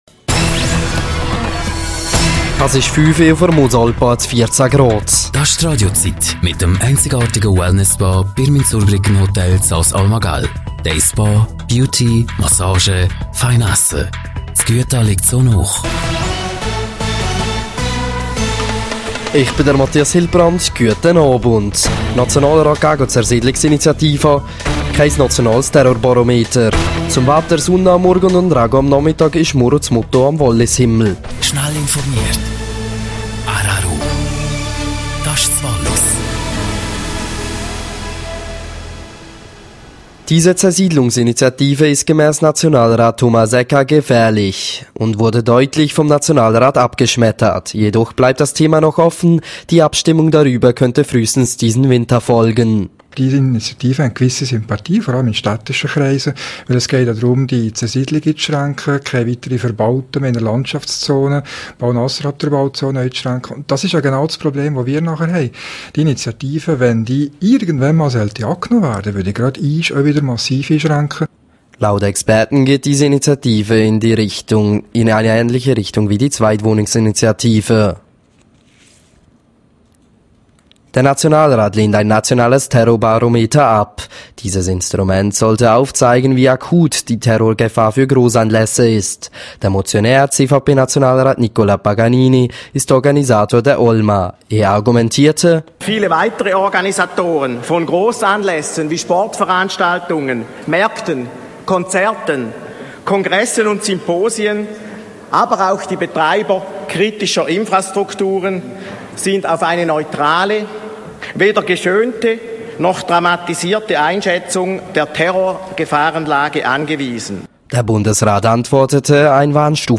17:00 Uhr Nachrichten (4.55MB)